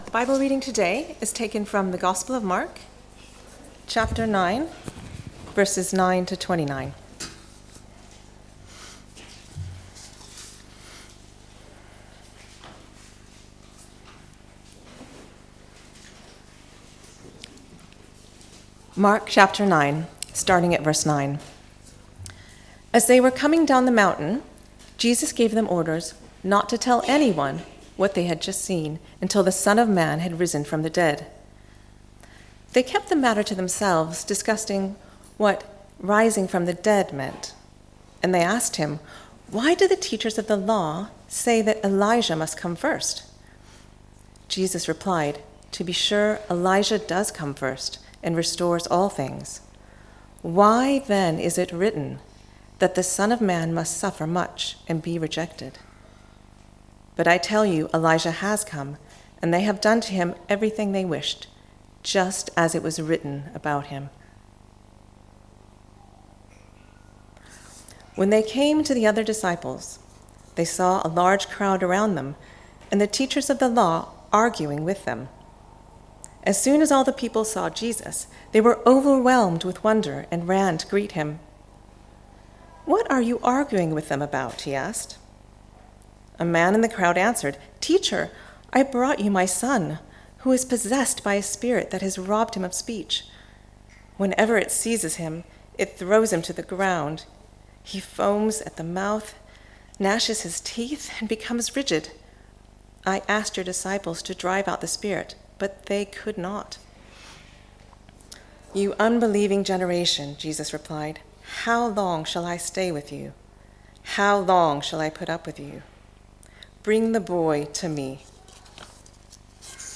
Mark 9:9-29 Service Type: Weekly Service at 4pm « “This is my beloved Son.